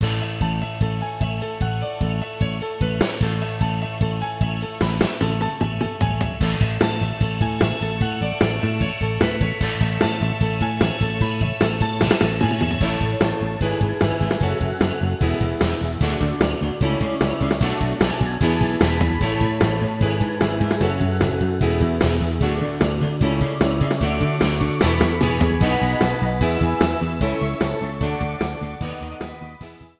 Trimmed and fade out
Fair use music sample